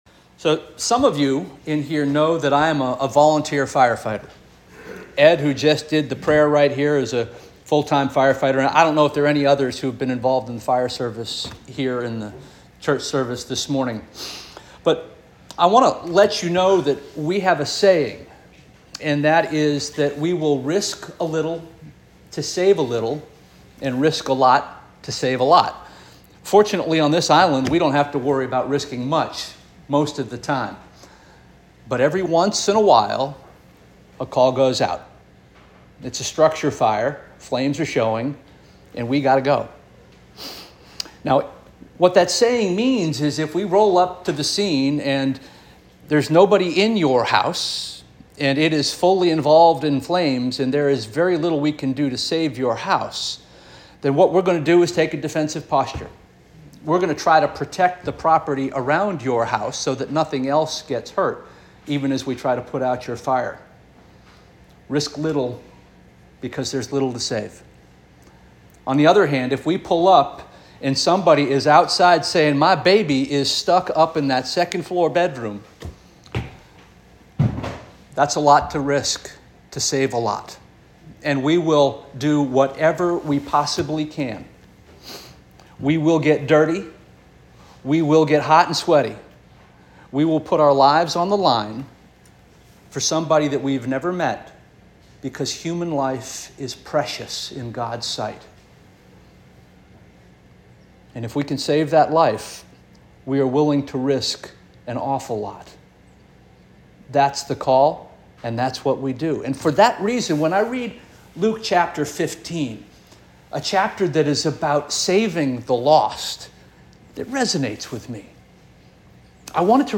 July 7 2024 Sermon